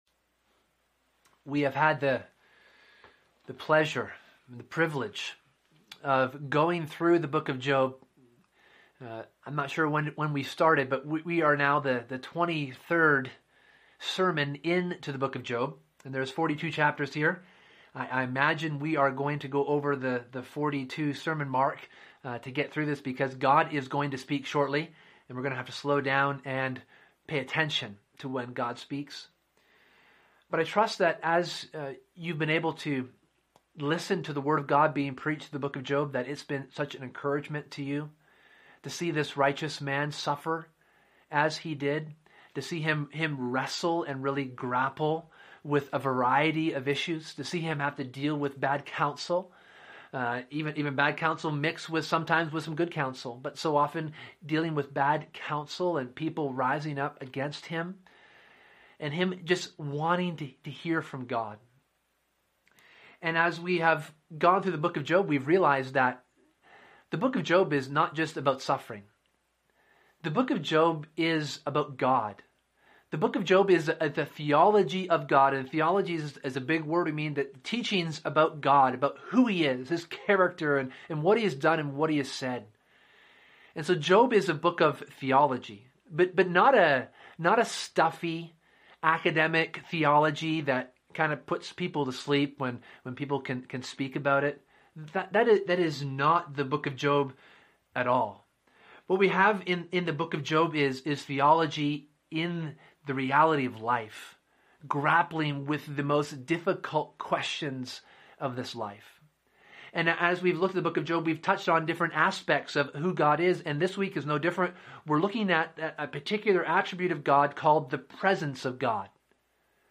Sermon: The Presence of God